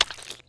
gibhit4.wav